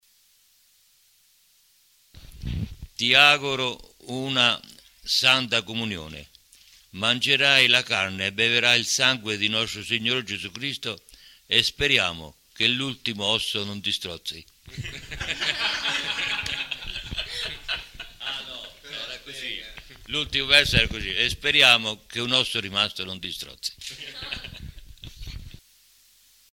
La registrazione è del 2004 ed è stata effettuata dai ragazzi dell’IPC di Cupra Marittima e dai loro insegnanti per Dream Radio Stream, la loro web radio, fu la prima in Italia di un istituto d’istruzione superiore. Le letture riguardano delle brevi poesie e alcuni aforismi.